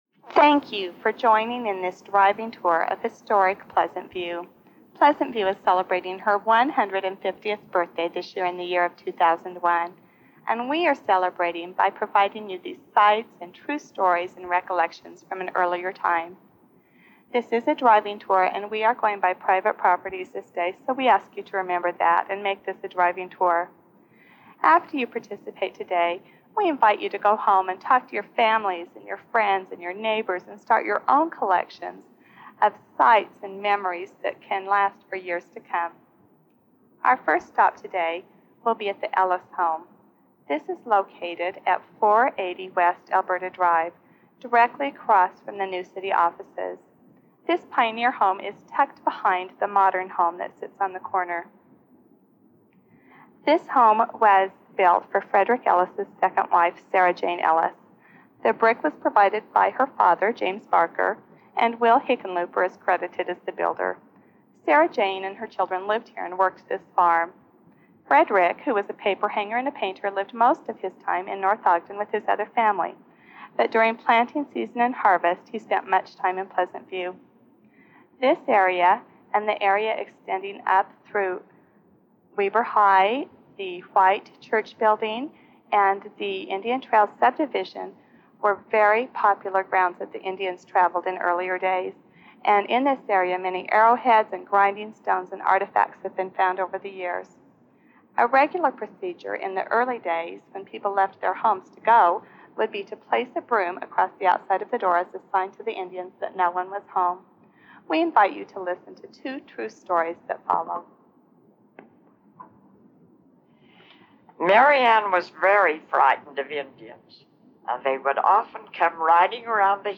audio car tour – PV Heritage Foundation
Here is the new, digitally-enhanced audio tour for your listening pleasure.
Follow the numbers on the map below in your car, on your bike, or walking as you listen to the tour guide.